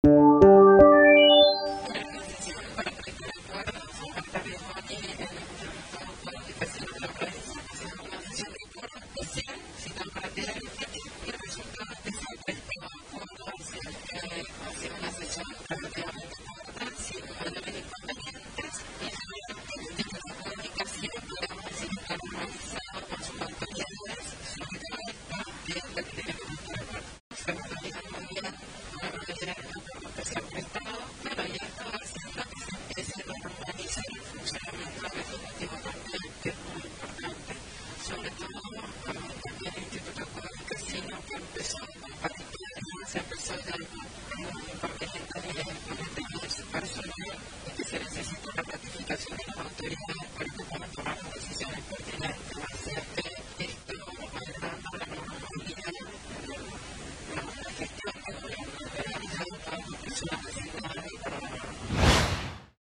Audio vicegobernadora Laura Montero sobre la realización de la Sesión de Acuerdos